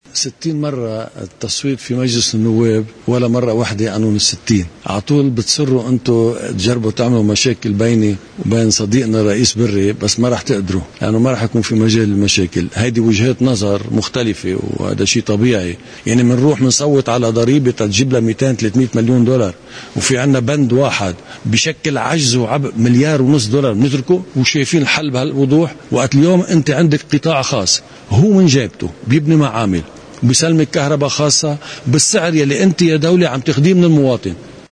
مقتطف من حديث رئيس القوات سمير جعجع مع بعبدا بعد لقائه الرئيس عون: